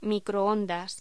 Locución: Microondas
voz